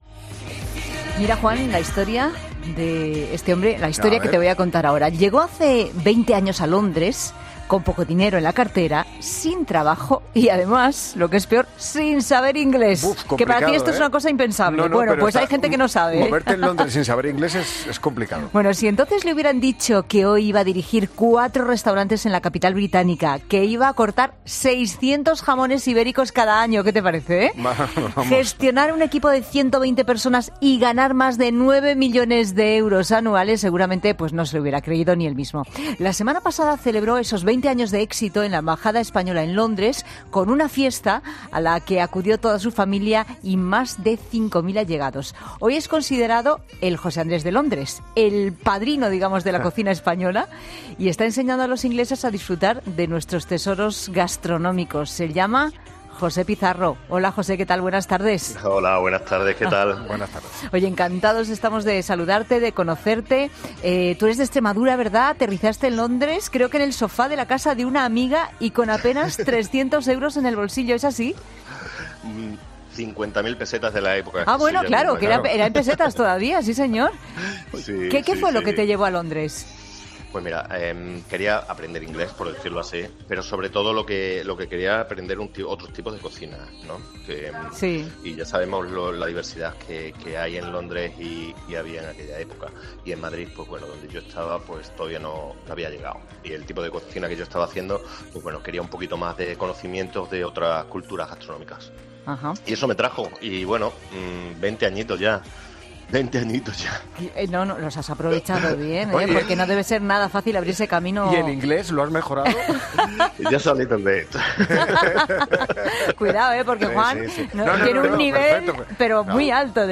Pizarro ha estado 'La Tarde' de COPE para explicar más detalles de cómo es el día a día en Londres y algunos de sus secretos para conseguir este éxito de 20 años: “Vine a aprender inglés y otros tipos de cocina. Eso me trajo y después de dos décadas estoy feliz y con ganas de seguir trabajando”.